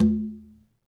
Quinto-HitN_v1_rr2_Sum.wav